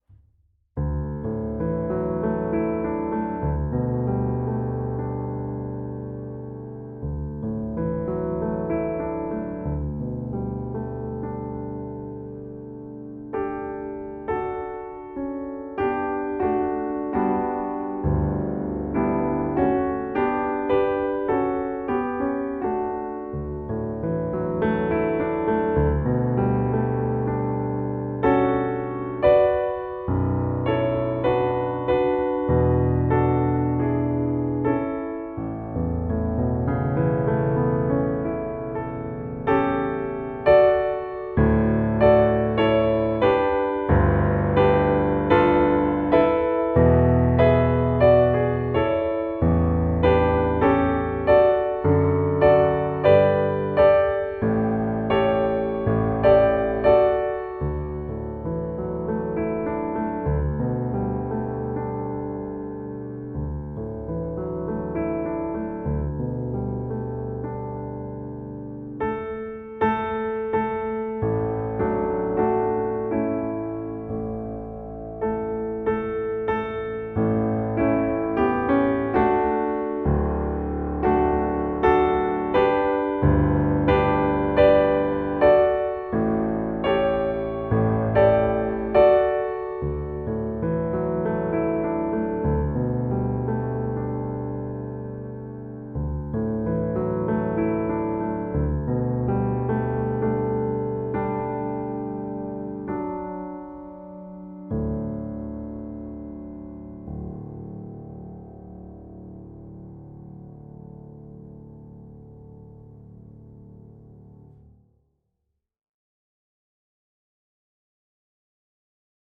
Includes a short intro and outro.